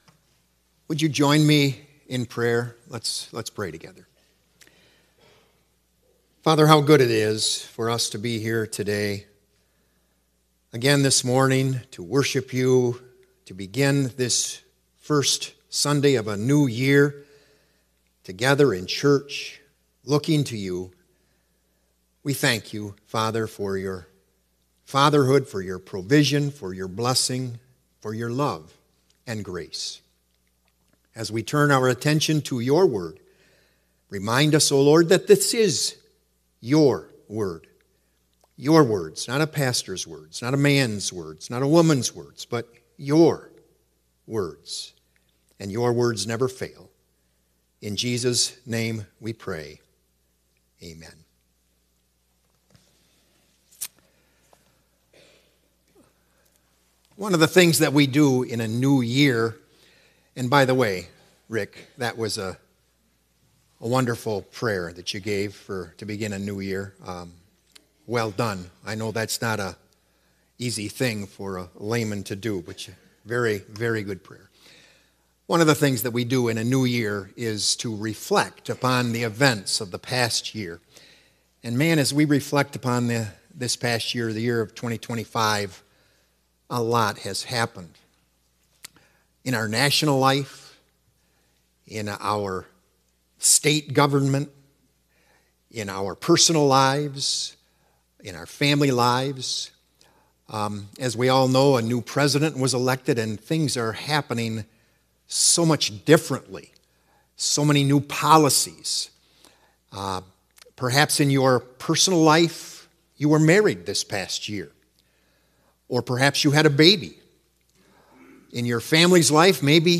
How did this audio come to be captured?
“Old Words for a New Year” January 4 2026 A.M. Service